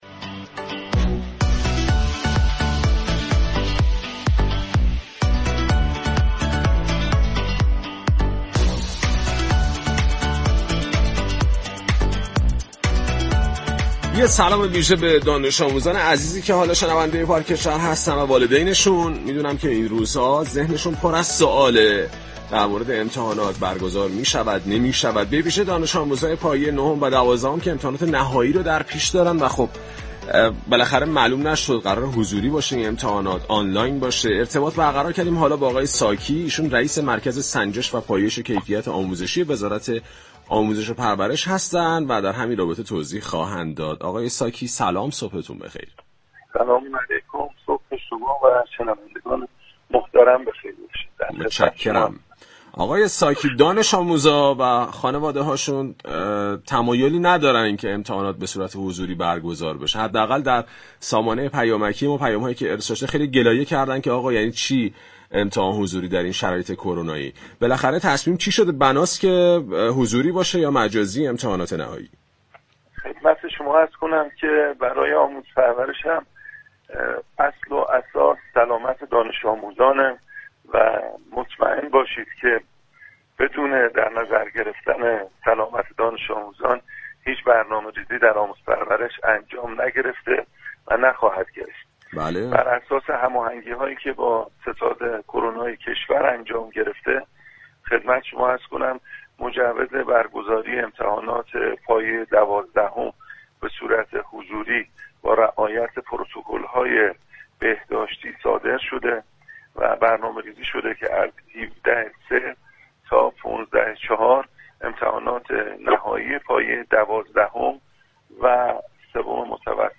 خسرو ساكی در گفتگو با "پارك شهر" اصلی‌ترین اصل و اساس وزارت آموزش و پرورش را اهمیت به سلامت دانش آموزان خوانده و گفت: خانواده‌ها مطمئن باشند بدون در نظر گرفتن سلامت فرزندانشان هیچ برنامه‌ریزی در شرایط فعلی در آموزش و پرورش انجام نگرفته و نخواهد گرفت.